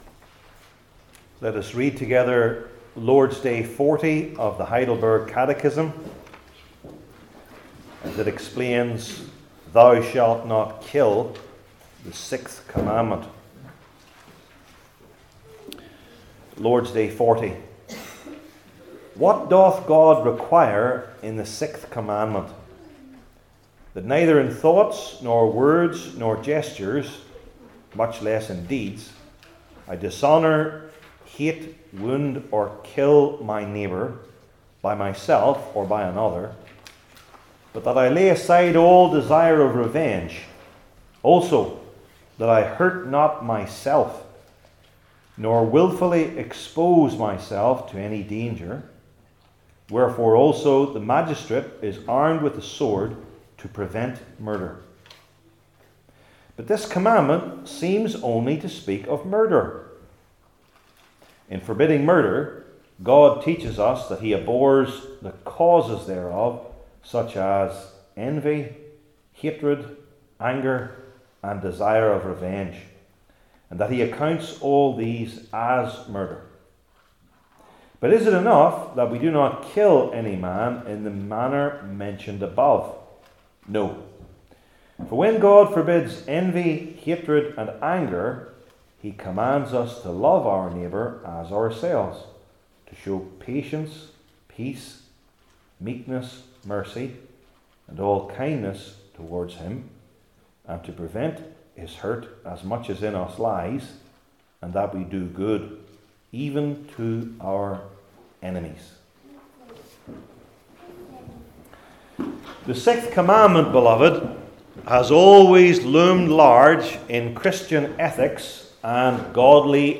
Matthew 5:1-26 Service Type: Heidelberg Catechism Sermons I. The Meaning II.